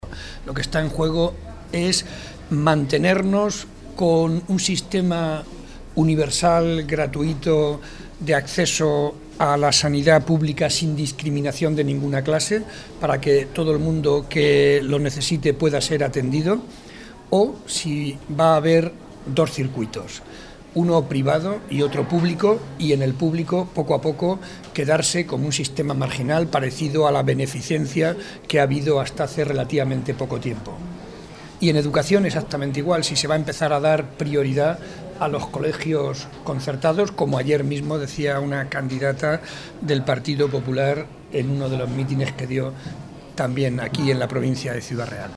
Mantener un sistema público o abrir las puertas a uno privado. Esto es lo que se juegan los ciudadanos en el próximo domingo, tal y como ha señalado el secretario regional del PSOE y candidato al Congreso de los Diputados por Ciudad Real, José María Barreda, en un acto público celebrado en La Solana ante más de 300 personas junto al secretario provincial, Nemesio de Lara, y el alcalde de La Solana, Luis Díaz-Cacho.
Cortes de audio de la rueda de prensa